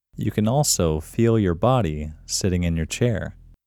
QUIETNESS Male English 3
The-Quietness-Technique-Voice-Over-3.mp3